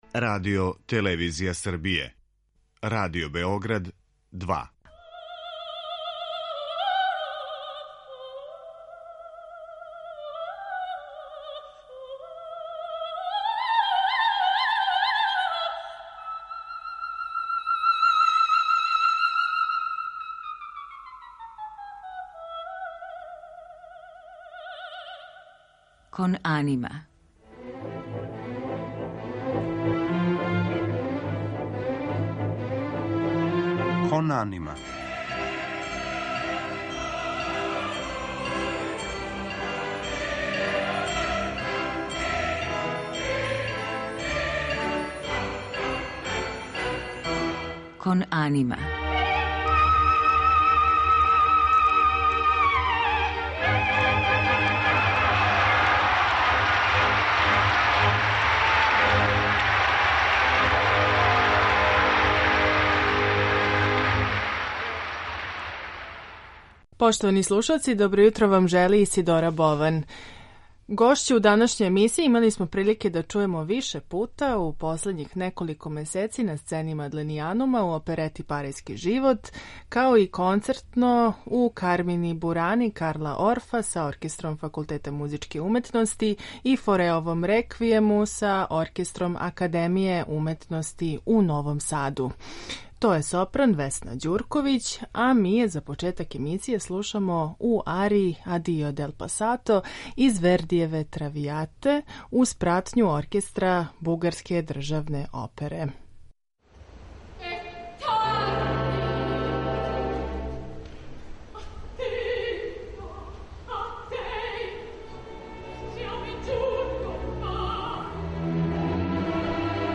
Њен портрет илустроваћемо аријама из Калманове оперете "Књегиња чардаша", као и аријама из Вердијеве "Травијате", те Гуноовог "Фауста".